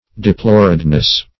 Meaning of deploredness. deploredness synonyms, pronunciation, spelling and more from Free Dictionary.
deploredness.mp3